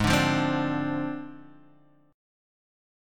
G#6b5 chord